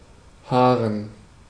Ääntäminen
IPA: [ˈhaːʁən]